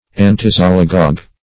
Search Result for " antisialagogue" : The Collaborative International Dictionary of English v.0.48: Antisialagogue \An`ti*si*al"a*gogue\, a. (Med.) Checking the flow of saliva.
antisialagogue.mp3